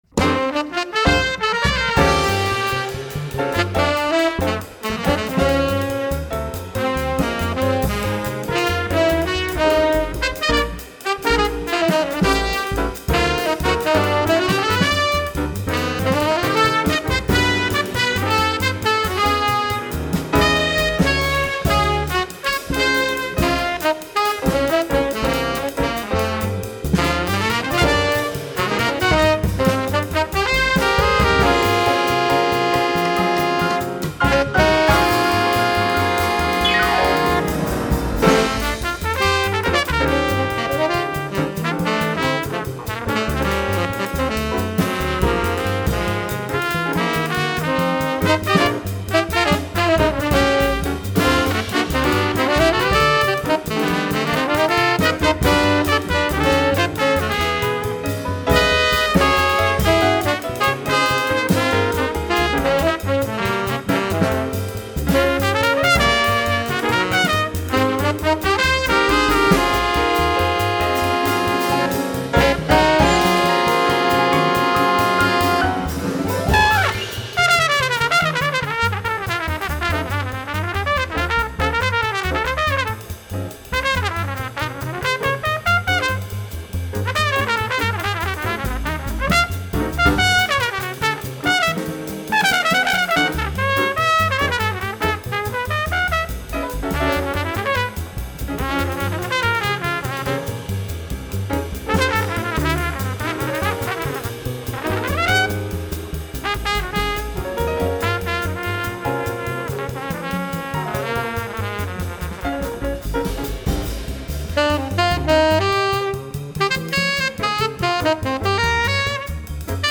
saxes
trumpet and flugelhorn
guitar
piano
bass
drum set